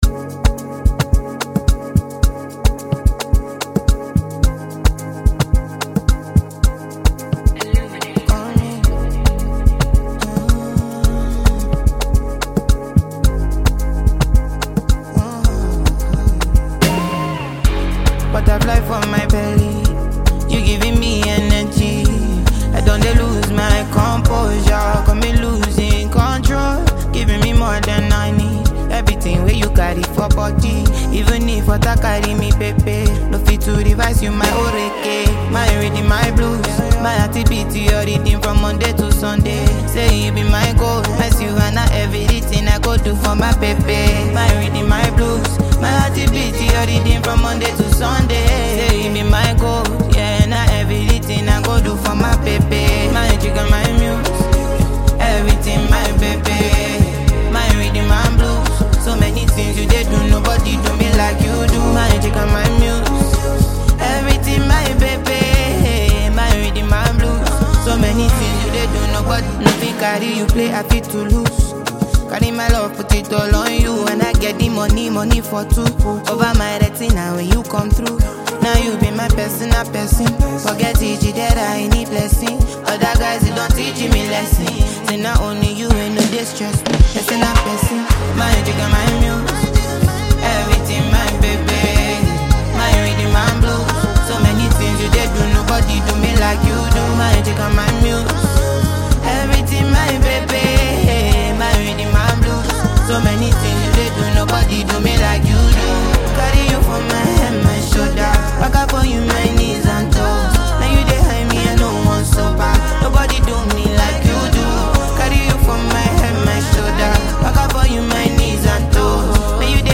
Well renowned Nigerian female artist
thrilling new gbedu song